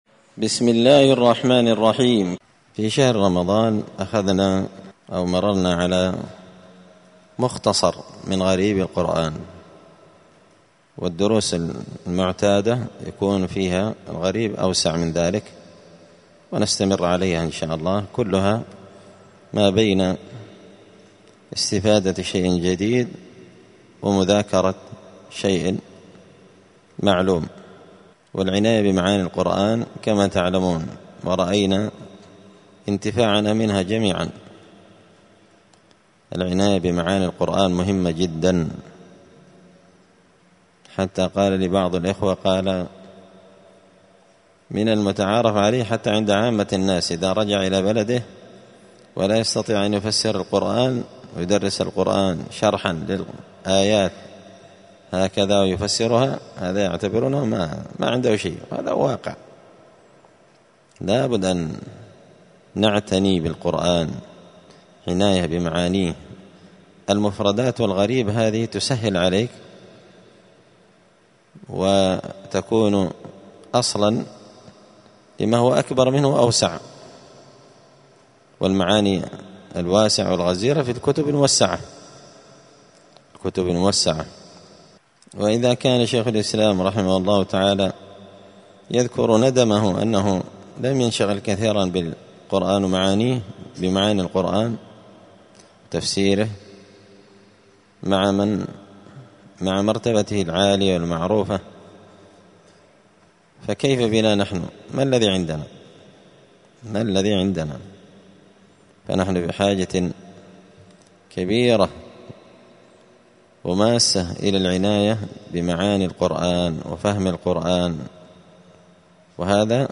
*(جزء الذاريات سورة الذاريات الدرس 159)*